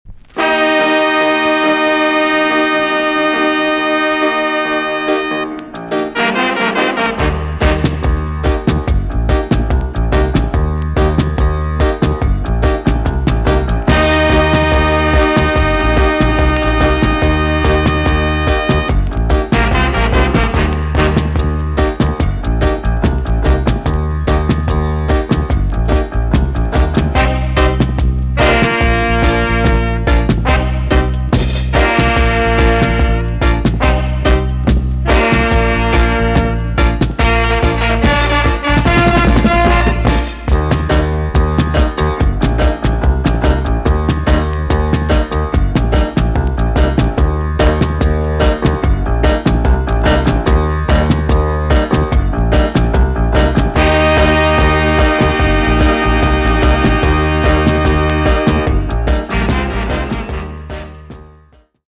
TOP >REGGAE & ROOTS
B.SIDE Version
EX- 音はキレイです。